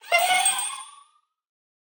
Minecraft Version Minecraft Version snapshot Latest Release | Latest Snapshot snapshot / assets / minecraft / sounds / mob / allay / idle_with_item4.ogg Compare With Compare With Latest Release | Latest Snapshot